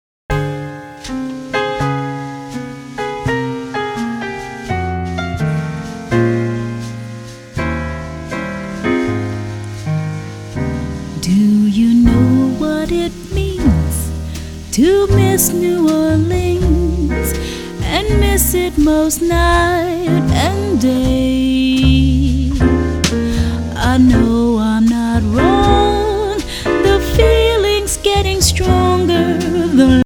brings timeless musical standards to life with demure allure and grace. From traditional Jazz to Latin to Africana